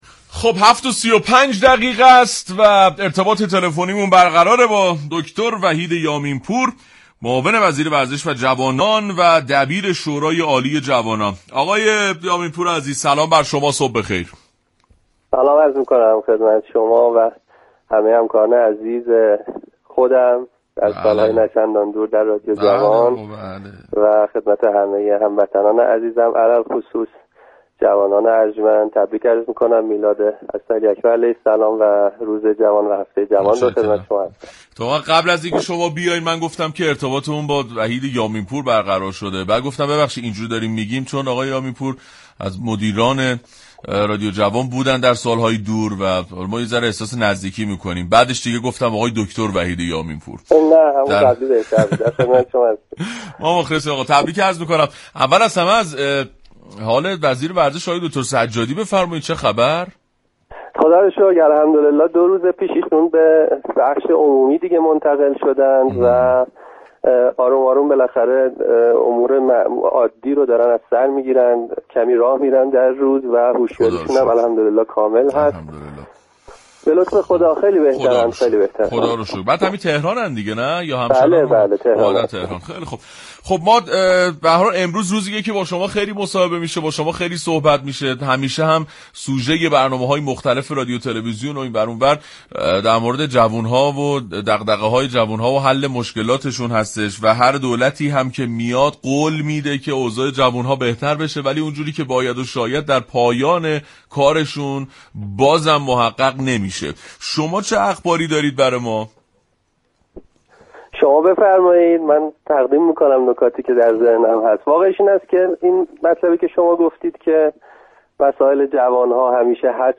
وحید یامین پور، در گفتگو با برنامه